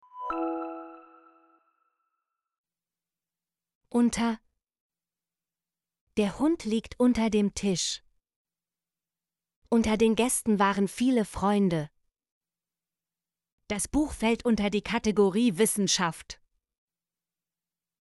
unter - Example Sentences & Pronunciation, German Frequency List